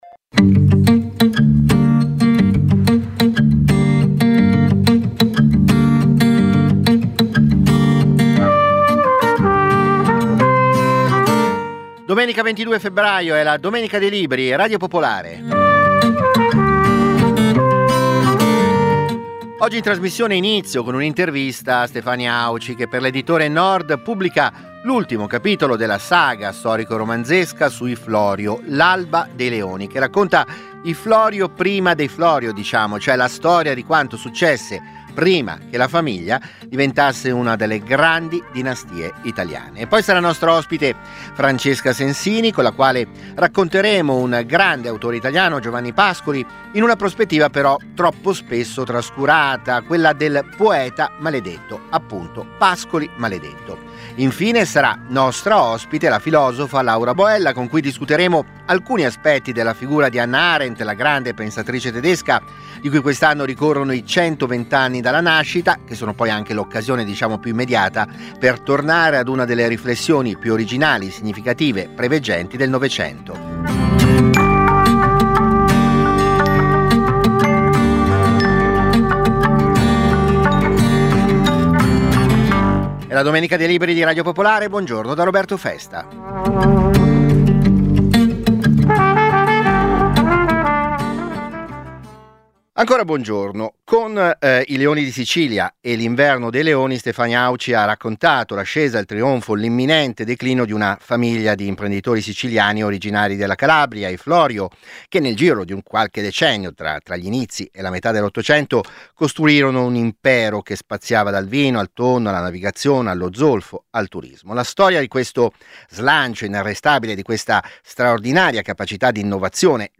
- Intervista a Stefania Auci, autrice di L'alba dei leoni. La saga dei Florio (Editrice Nord).